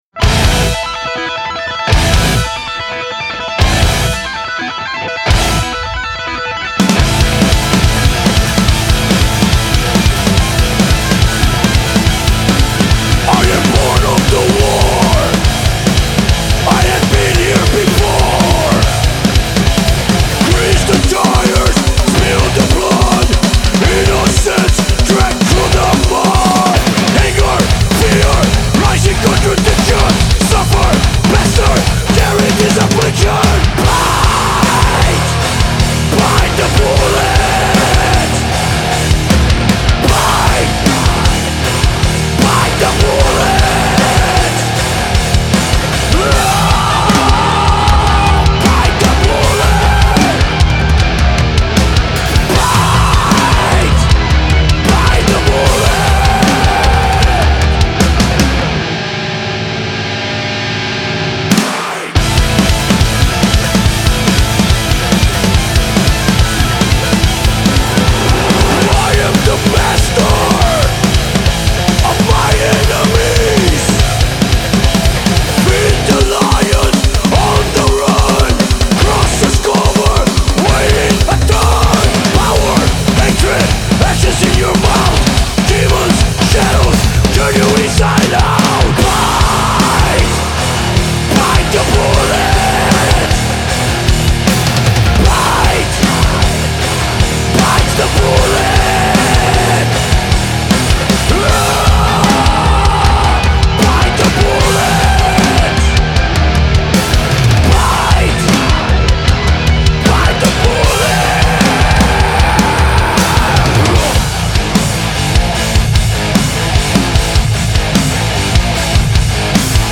Genre : Trash Metal